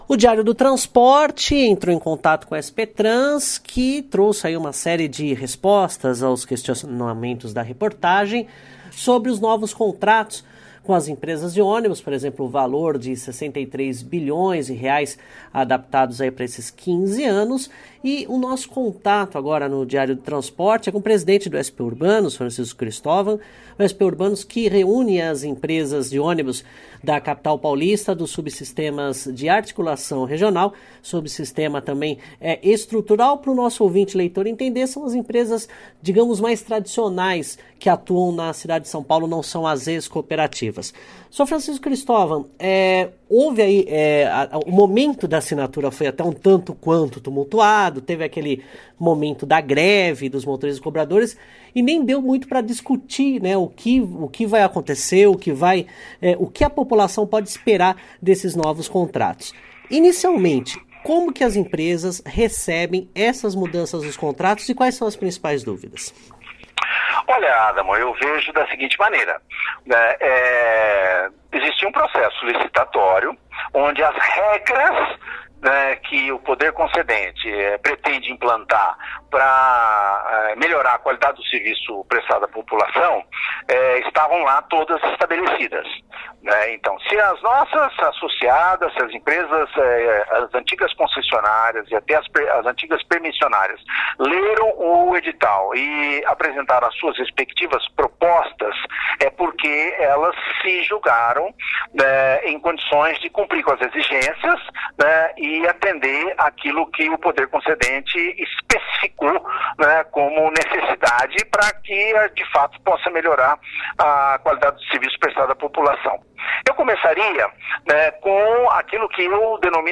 ENTREVISTA: Empresas de ônibus de São Paulo acreditam que redução de prazo de contratos para 15 anos não terá impactos em tarifas e subsídios